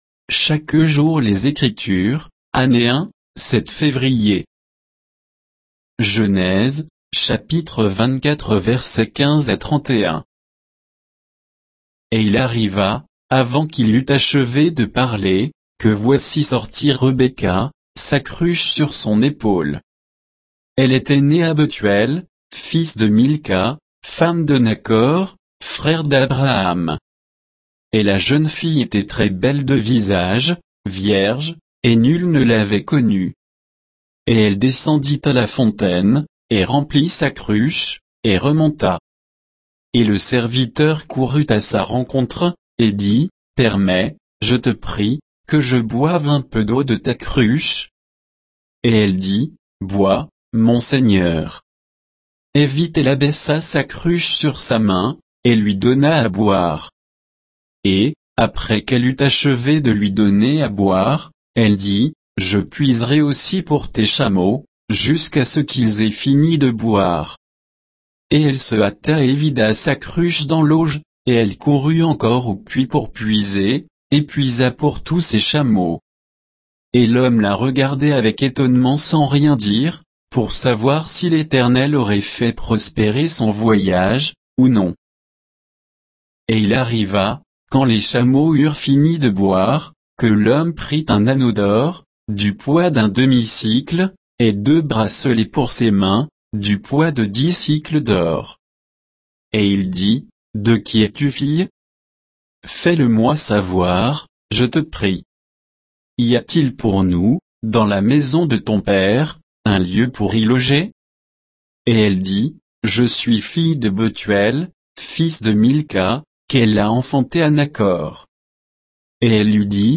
Méditation quoditienne de Chaque jour les Écritures sur Genèse 24